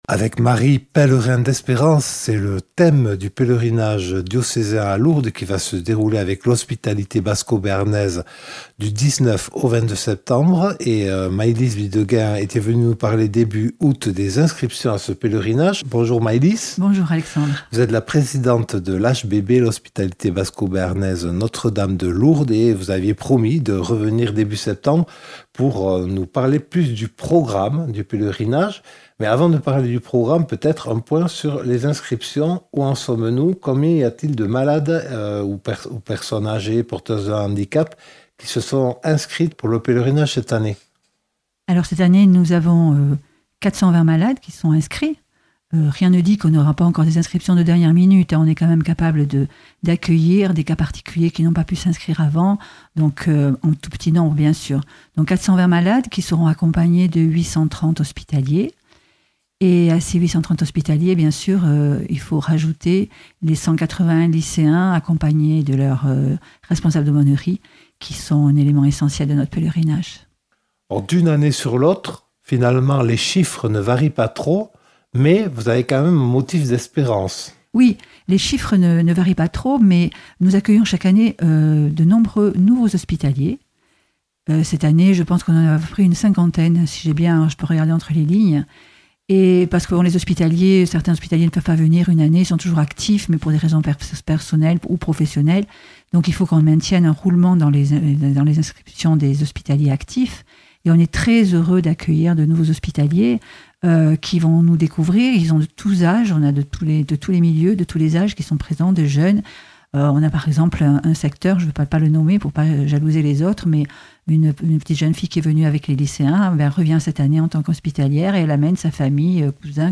Du 19 au 22 septembre, c’est le pèlerinage à Lourdes avec l’Hospitalité basco-béarnaise. Dimanche 21 septembre, messe à 10h à Ste Bernadette présidée par Mgr Aillet. Interview